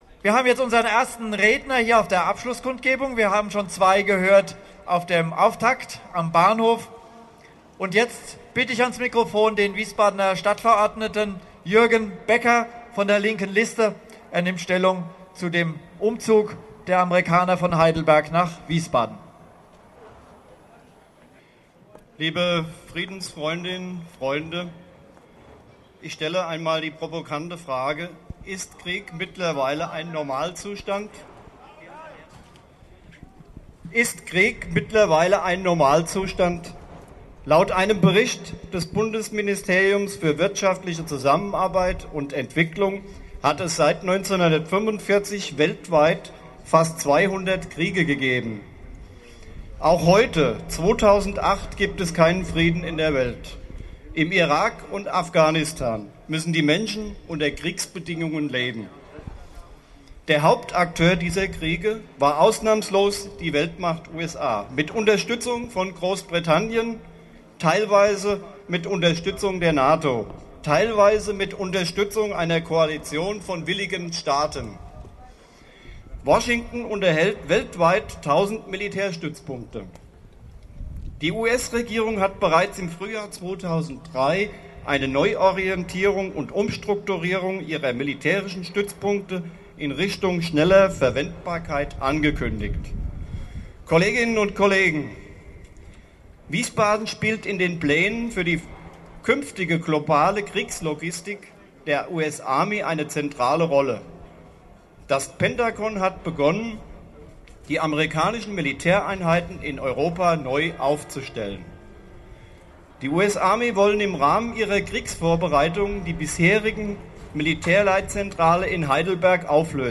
Stadtverordneter und sozialpolitischer Sprecher der Linken Liste in Wiesbaden (LiLi)
Rede als MP3